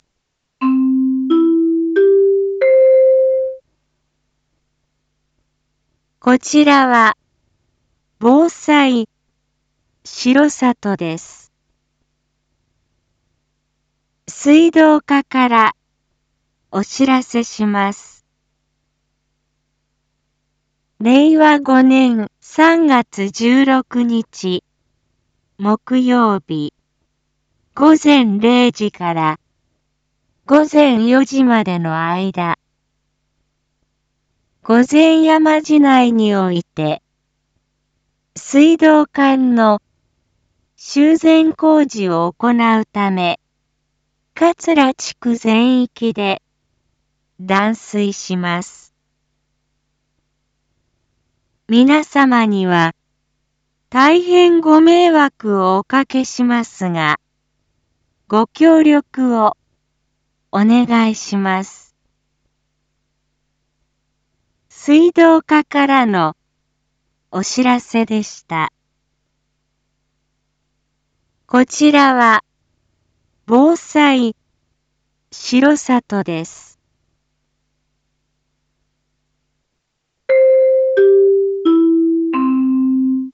一般放送情報
Back Home 一般放送情報 音声放送 再生 一般放送情報 登録日時：2023-03-15 19:06:24 タイトル：水道断水のお知らせ（桂地区限定） インフォメーション：こちらは、防災しろさとです。